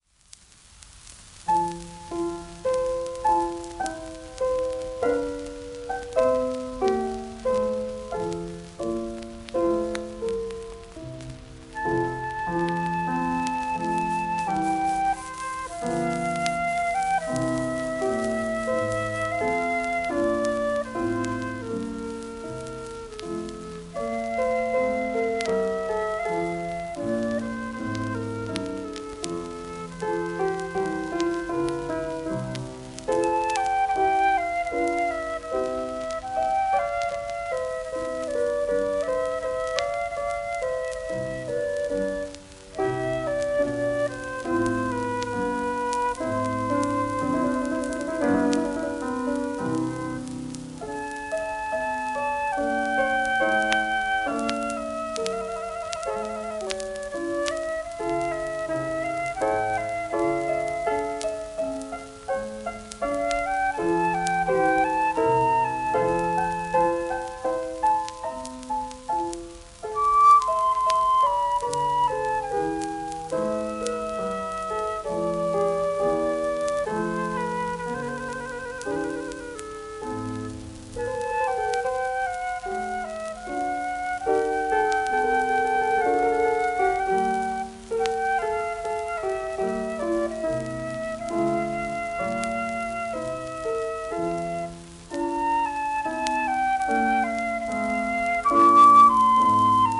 試聴:フルートとチェンバロの為のソナタヘ長調